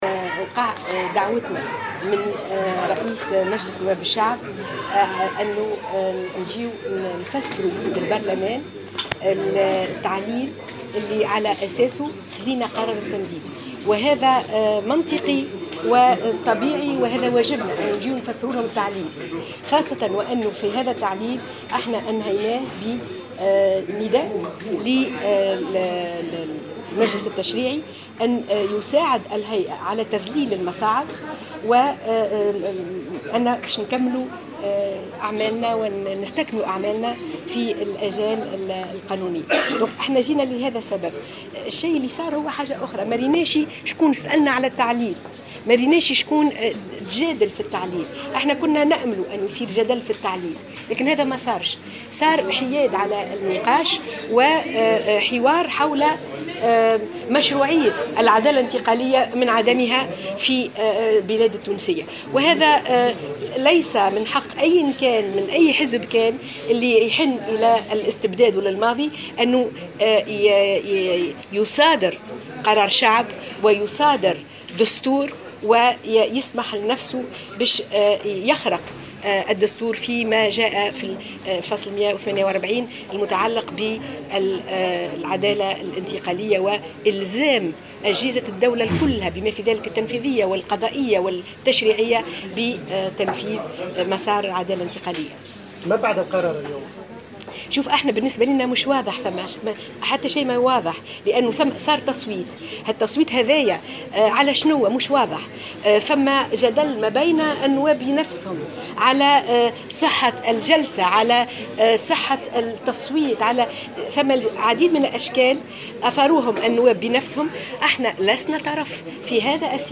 قالت رئيسة هيئة الحقيقة والكرامة سهام بن سدرين في تصريح لمراسلة الجوهرة "اف ام" اليوم الأربعاء على هامش ندوة صحفية عقدتها إن النقاش حول عمل الهيئة داخل البرلمان حاد عن مساره وهدفه وتحول الحوار حول مشروعية العدالة الانتقالية من عدمها داخل البلاد التونسية.